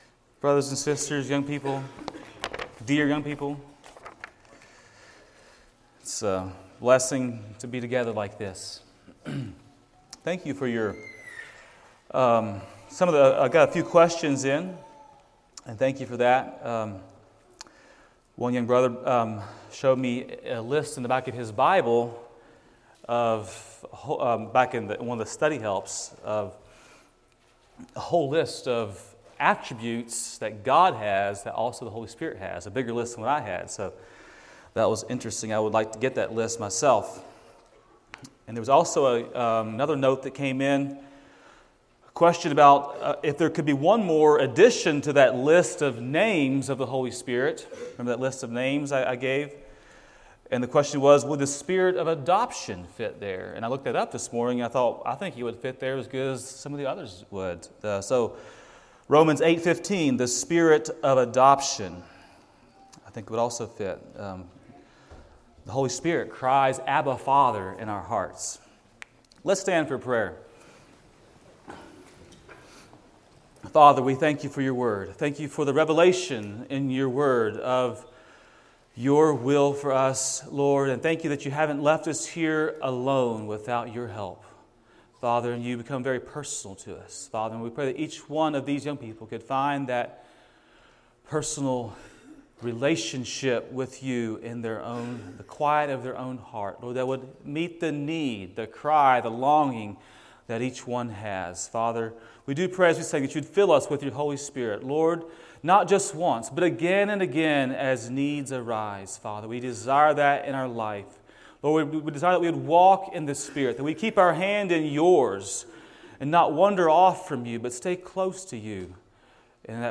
A message from the series "Bible Boot Camp 2025."